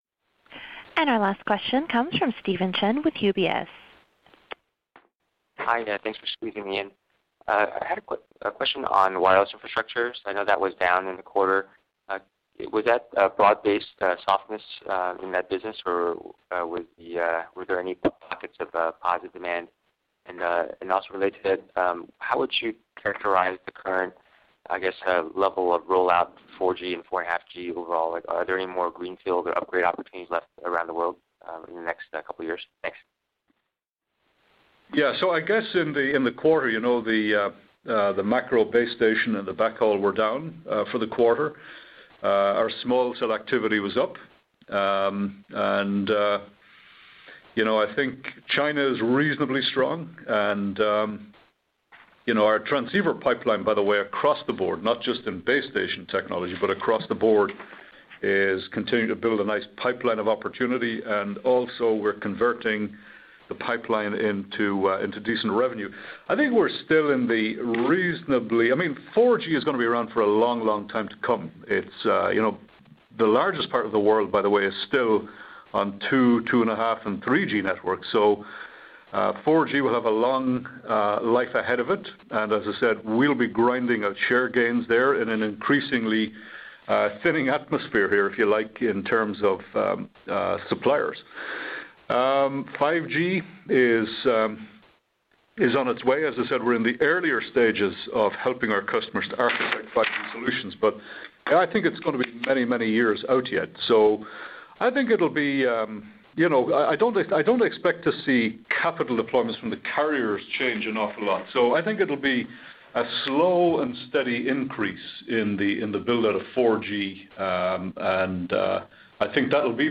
responding to a question during the fiscal Q4 earnings call on November 22, 2016.